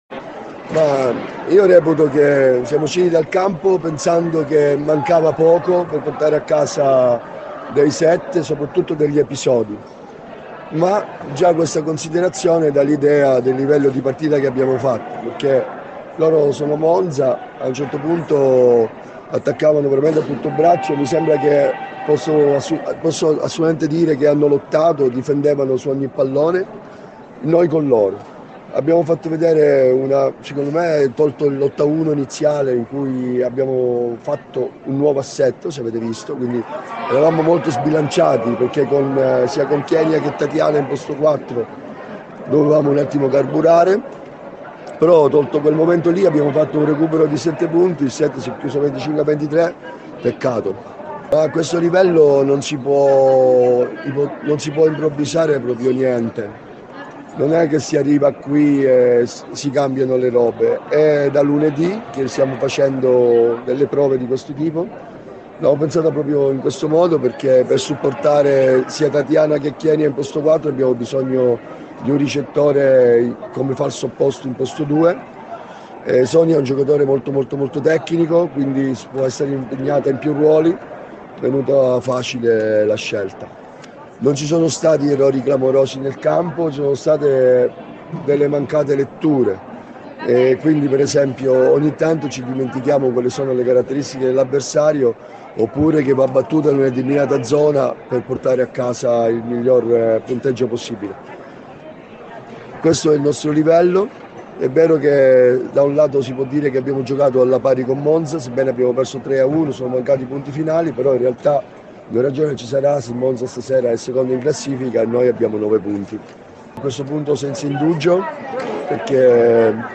Le interviste post partita